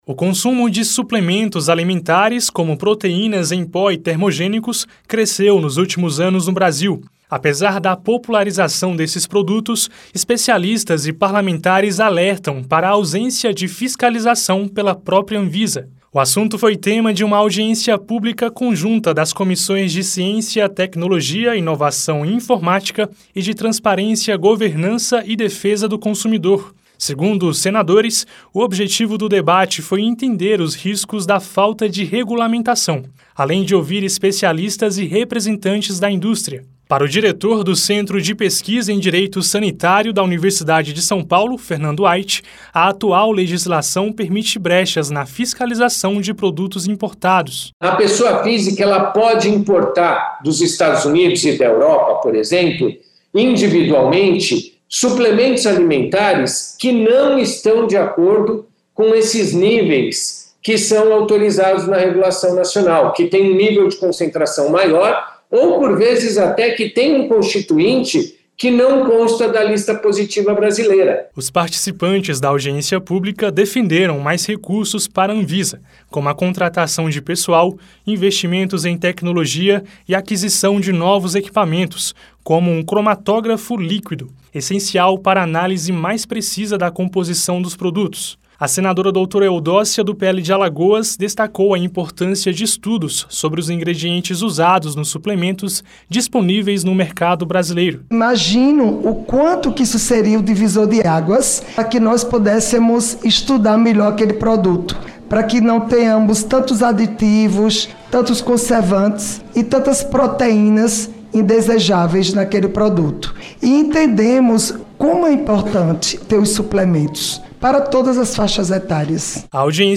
A Comissão de Ciência, Tecnologia, Inovação e Informática (CCT) e a de Transparência, Governança, Fiscalização e Controle e Defesa do Consumidor (CTFC) discutiram em audiência pública, nesta quarta-feira (23), o crescimento do consumo de suplementos alimentares no Brasil sem o devido controle sanitário. Os especialistas alertaram para o risco do uso de substâncias não autorizadas e destacaram brechas na importação de produtos. Senadores defenderam mais investimentos na Anvisa, como a compra de equipamentos para análise da composição dos suplementos.